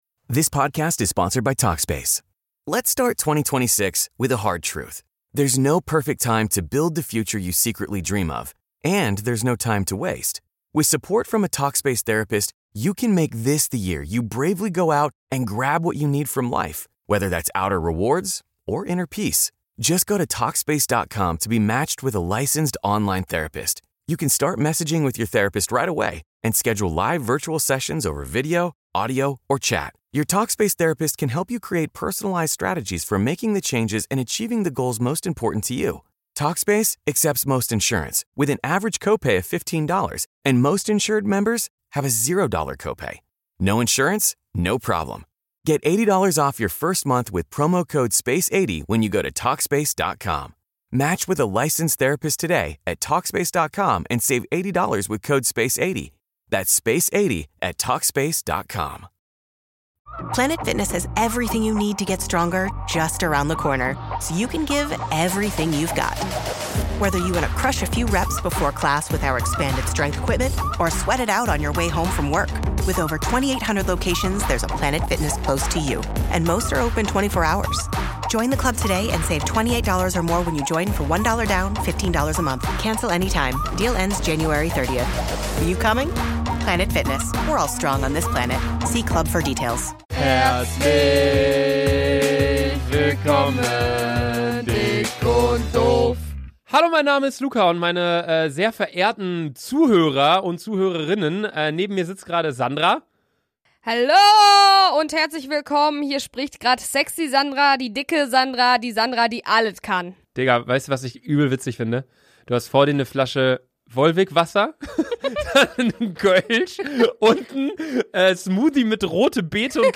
Genres: Comedy
Trailer: